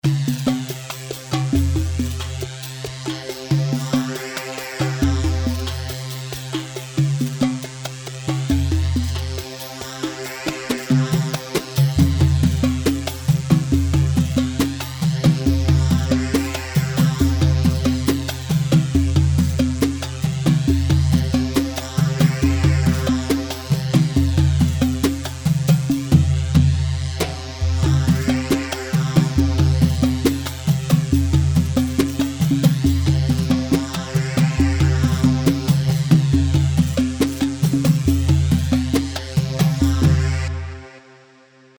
Rhumba 4/4 138 رومبا
Rhumba-138-mix.mp3